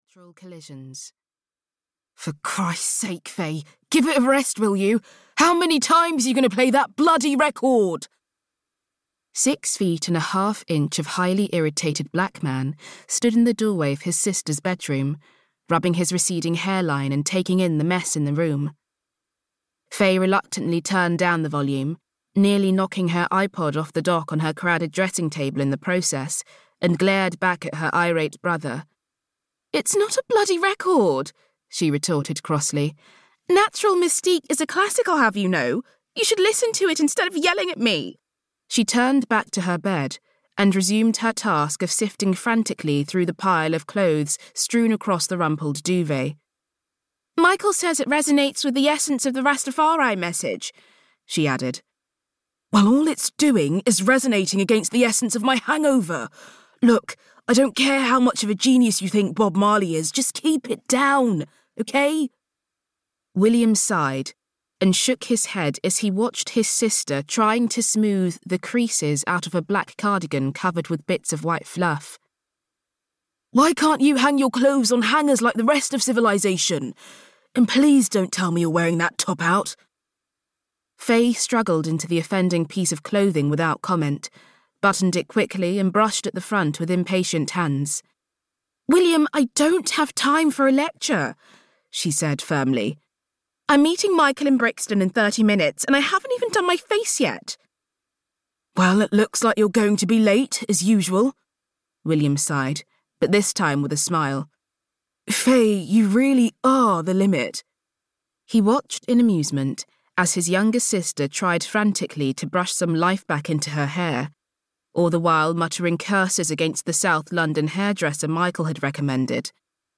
From Pasta to Pigfoot (EN) audiokniha
Ukázka z knihy